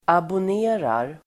Uttal: [abån'e:rar]